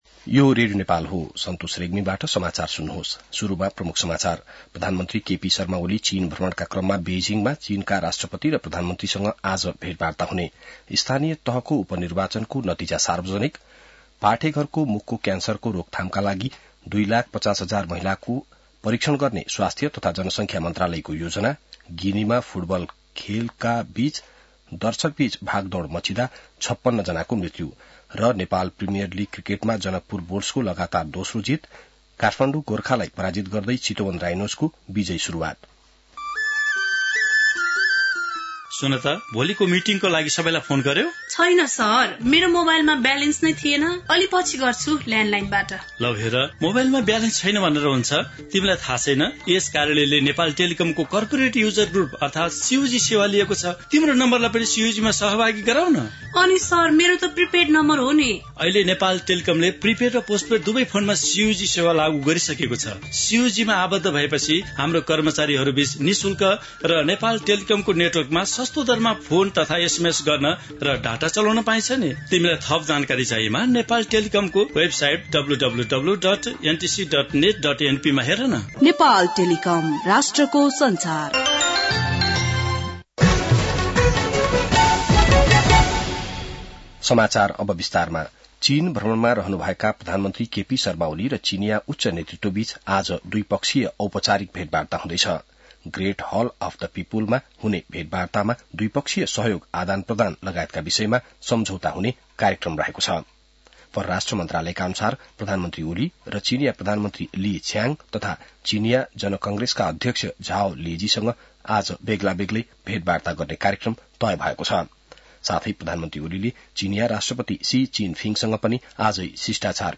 बिहान ७ बजेको नेपाली समाचार : १९ मंसिर , २०८१